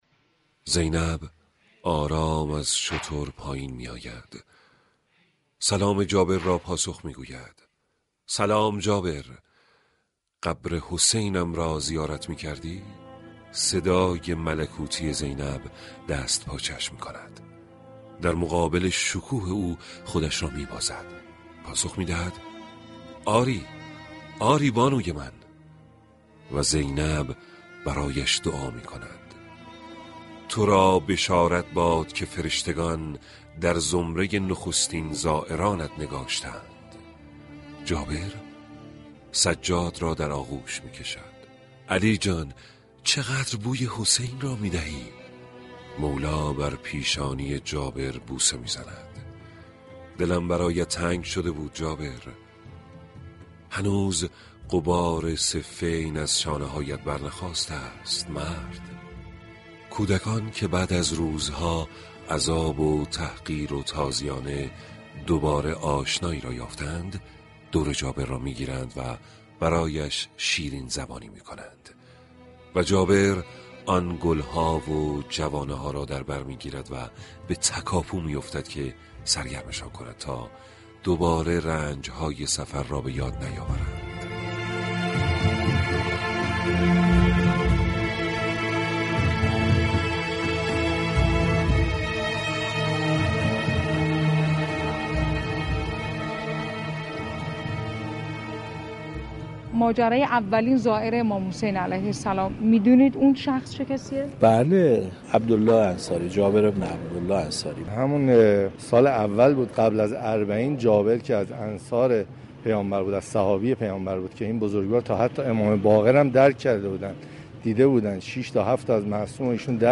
به گزارش روابط عمومی رادیو صبا، «فصل شیدایی» عنوان یكی از ویژه برنامه های روز اربعین این شبكه رادیویی است كه با مخاطبان از حال و هوای زیارت امام حسین (ع) در این روز گفتگومی كند.